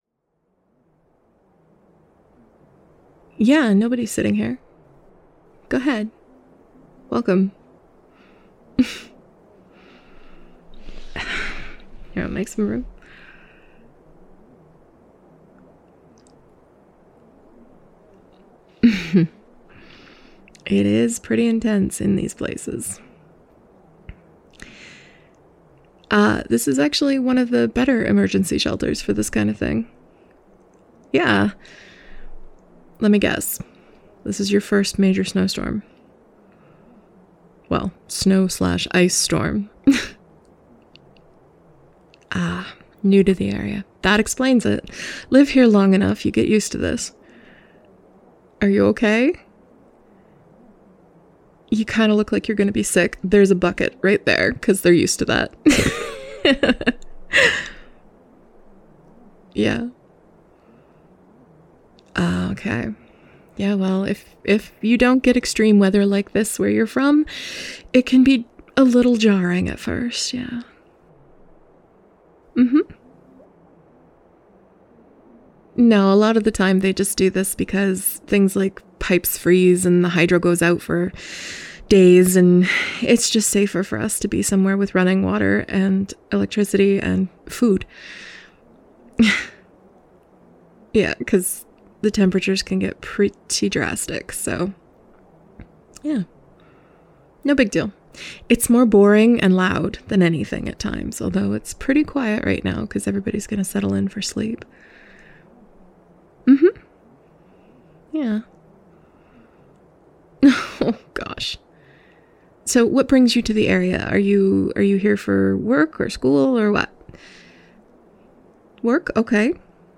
[F4M]
[Older Woman]
[Whispers]
[Soothing]